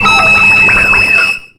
Cri de Neitram dans Pokémon X et Y.